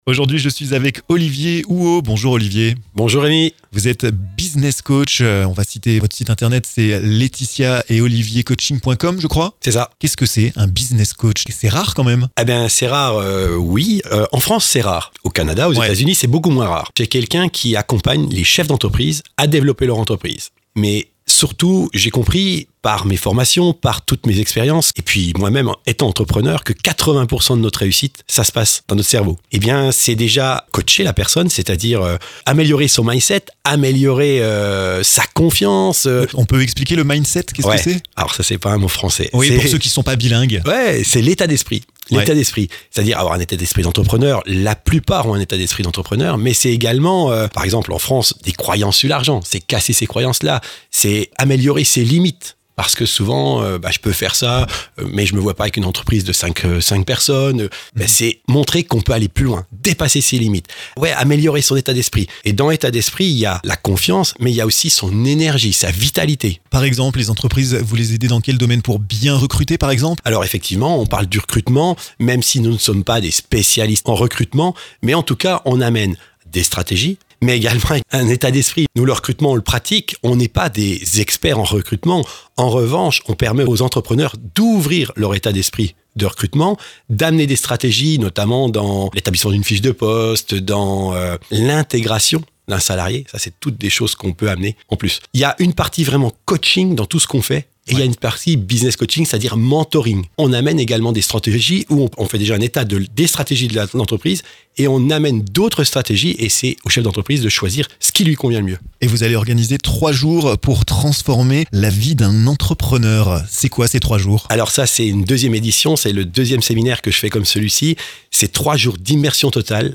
Retrouvez sa voix et ses explications dans ce podcast!